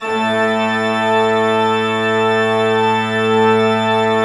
Index of /90_sSampleCDs/Propeller Island - Cathedral Organ/Partition F/MAN.V.WERK M